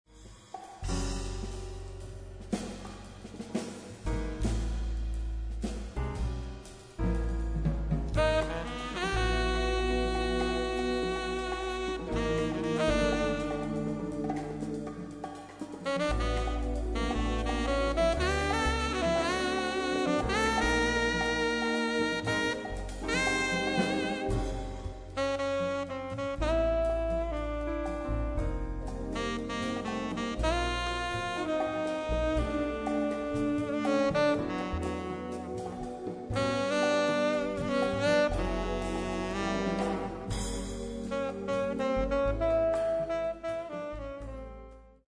piano
sax
bass
drums
percussion